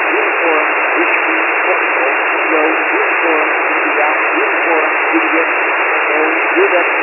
Random sequences of 10 characters are generated in white noise, band
( 5.0 S/N ratio)
Each file contains 10 random spelled out characters.